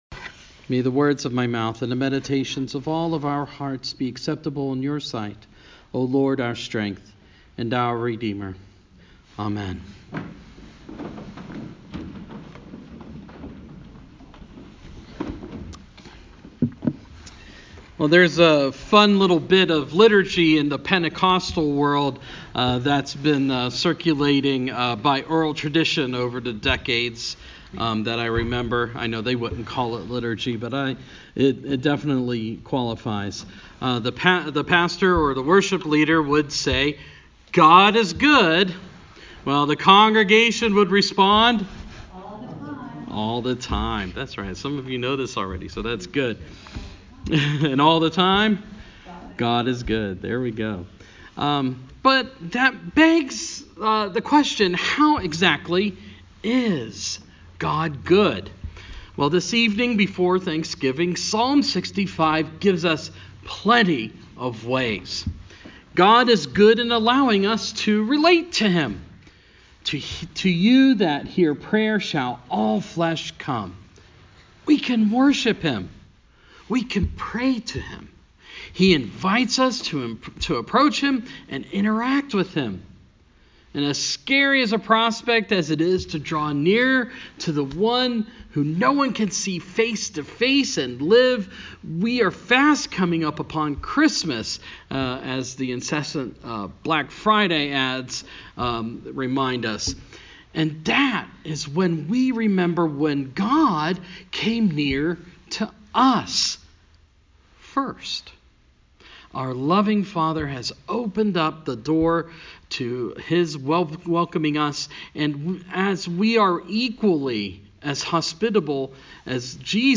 Thanksgiving Eve Homily